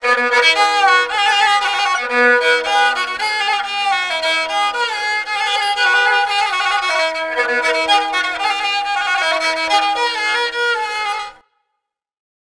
Index of /90_sSampleCDs/Sonic Foundry (Sony Creative Software) - World Pop/Stringed Instruments/Chinese